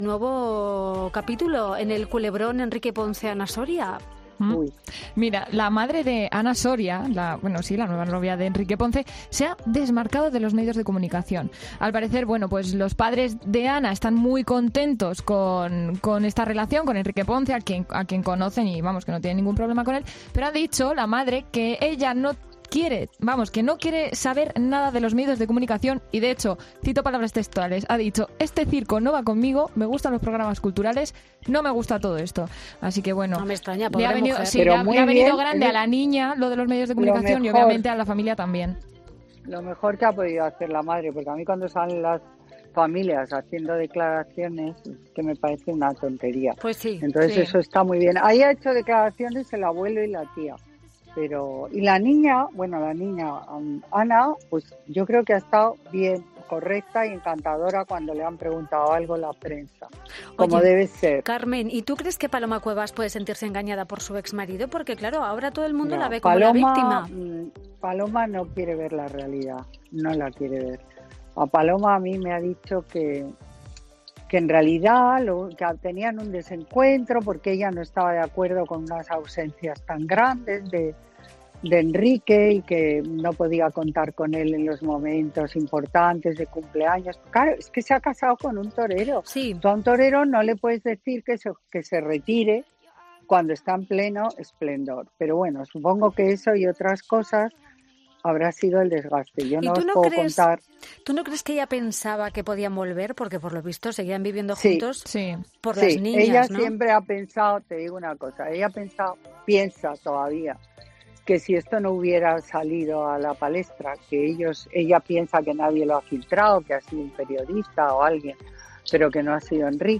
En la vuelta de Cristina a la dirección de ‘Fin de Semana’ y en el arranque de la nueva temporada, Lomana ha vuelto a su consultorio habitual, en el que la presentadora le cuestionaba por la pareja del momento y sobre los últimos rumores y publicaciones románticas.